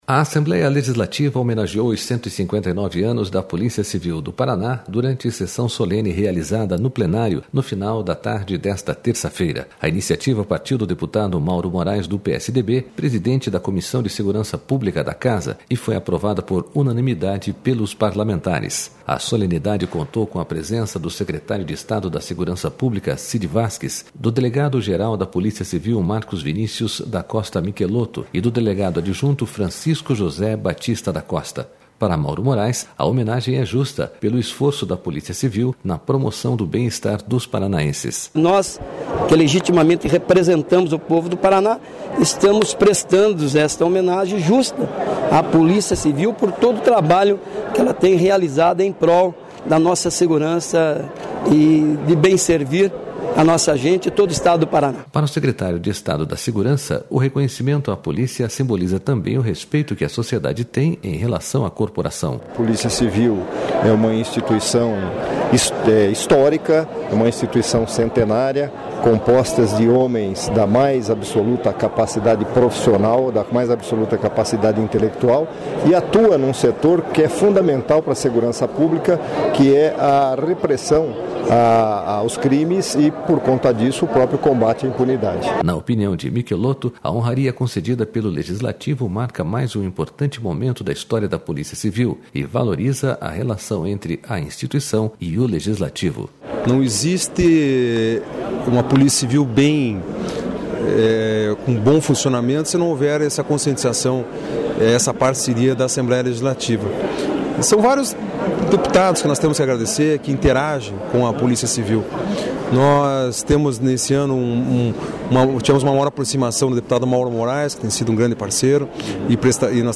A Assembleia Legislativa homenageou os 159 anos da Polícia Civil do Paraná, durante sessão solene realizada no Plenário, no final da tarde desta terça-feira.//A iniciativa partiu do deputado Mauro Moraes, do PSDB, presidente da Comissão de Segurança Pública da Casa, e foi aprovada por unanimidade pe...